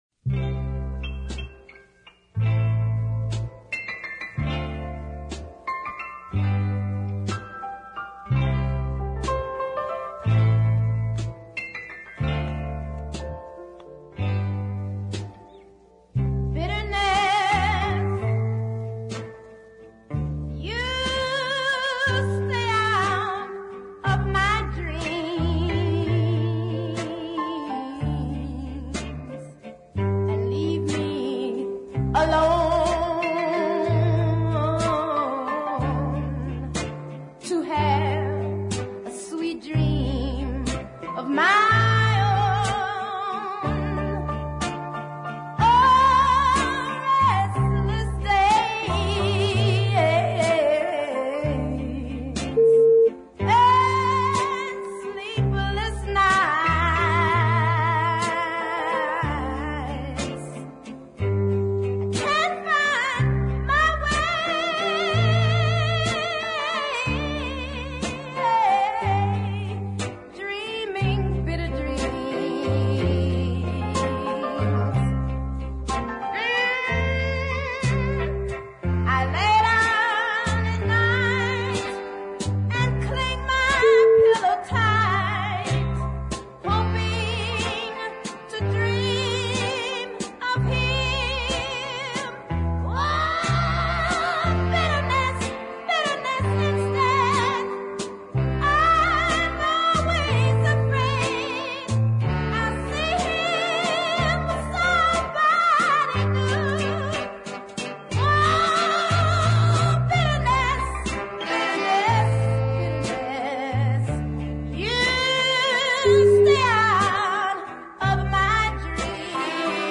the excellent ballad